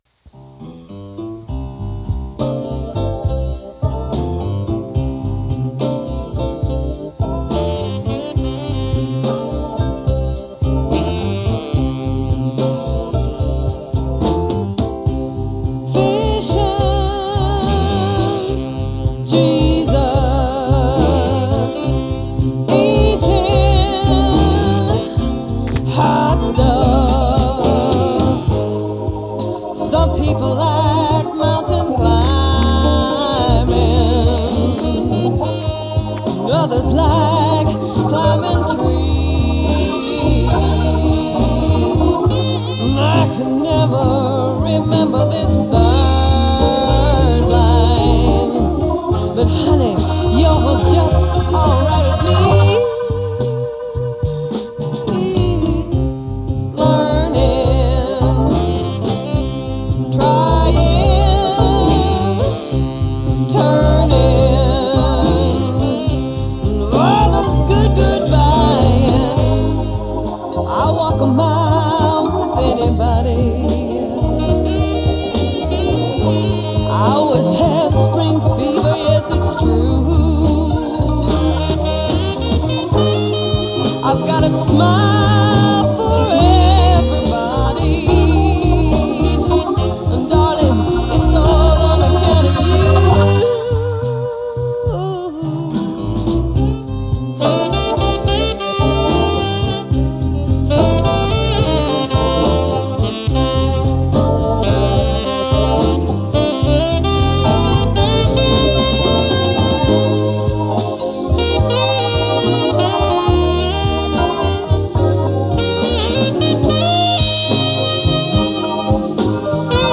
acoustic piano.
hammond electric organ.
saxophone.
electric bass.
drums. Recorded winter 1974 at A.R.T.I. in Farmingdale, NY.